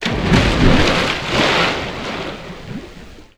SPLASH_Deep_02_mono.wav